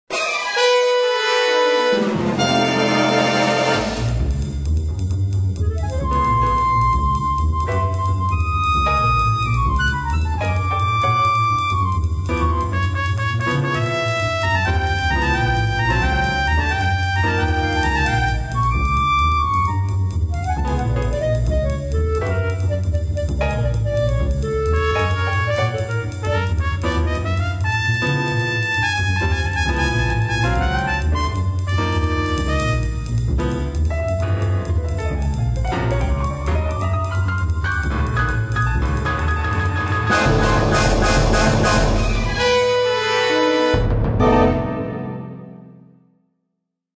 ~ САУНДТРЕК ~